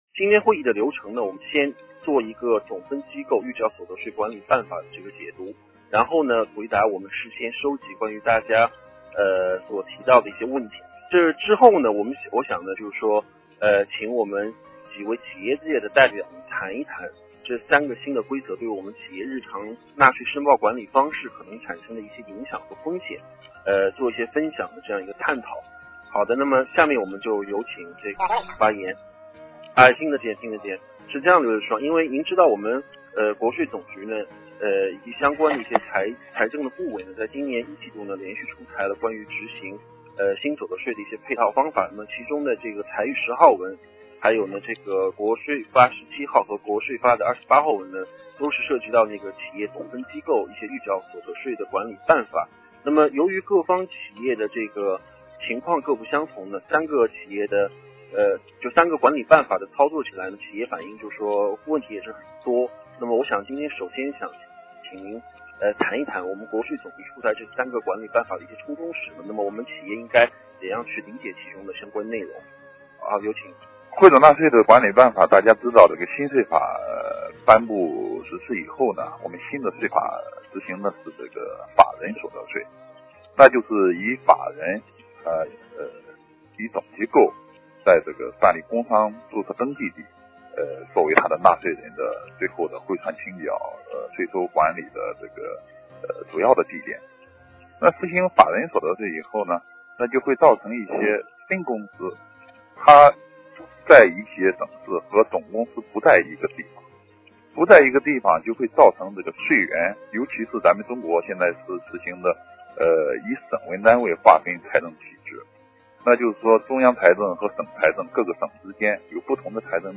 此次电话会议讨论了新企业总分机构预缴所得税及季度纳税申报管理办法。 特邀政府官员花费将近一个小时的时间分析了它将来的影响。政府官员也讨论并回答了企业所关心的内容。